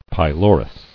[py·lo·rus]